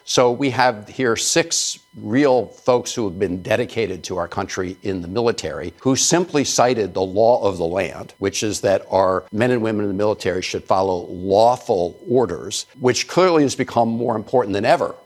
Kelly and other congressional leaders had said that armed forces personnel should not follow illegal orders. Senator Chris Van Hollen of Maryland was on ABC’s This Week and said the Senate would be in an uproar if that happened…